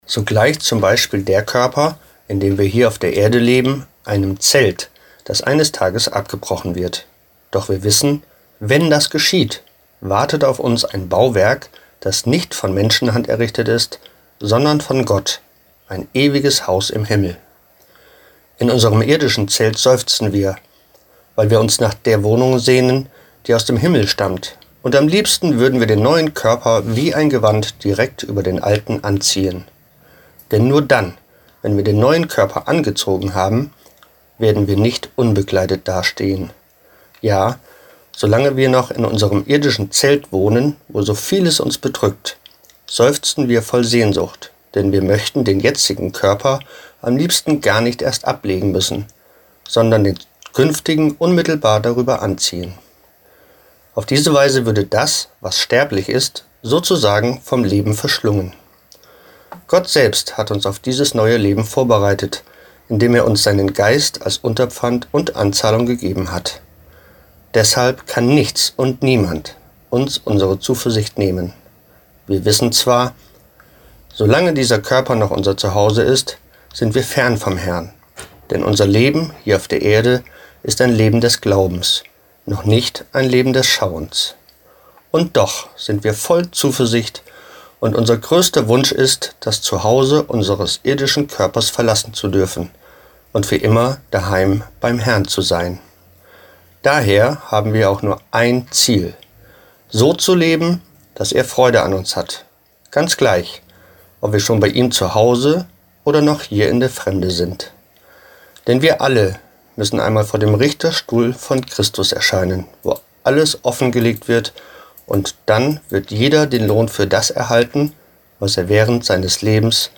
Textlesung aus 2. Korinther 5,1-10: